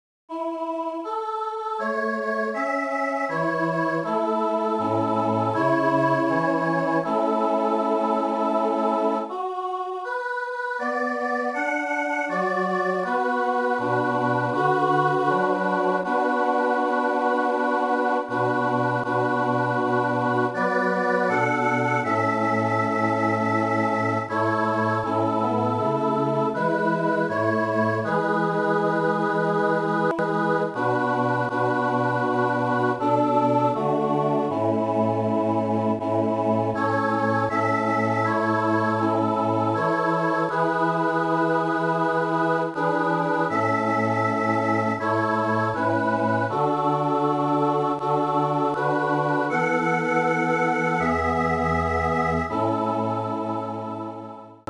Chor a cappella
Lamm_Gottes_Fuge.mp3